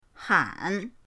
han3.mp3